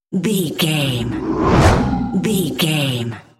Airy whoosh pass by large
Sound Effects
futuristic
pass by
sci fi